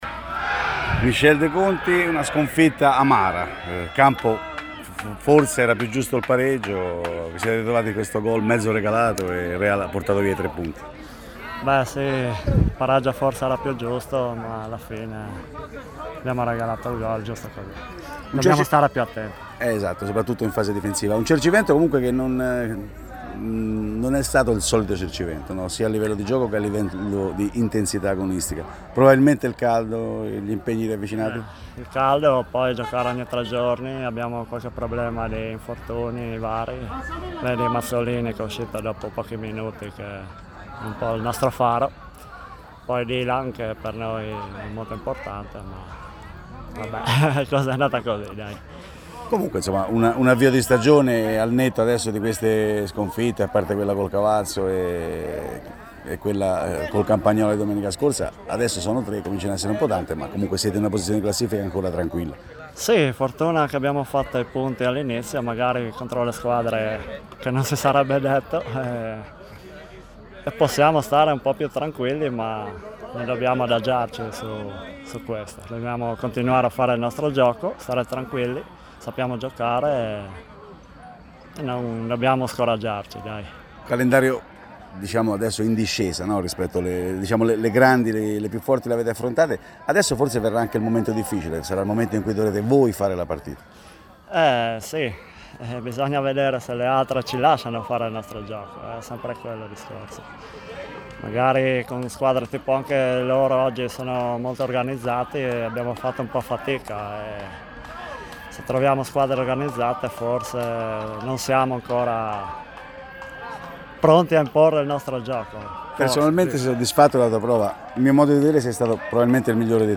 Proponiamo le audiointerviste registrate al termine dell’anticipo di Prima Categoria fra Cercivento e Real, vinta 1-0 dagli ospiti.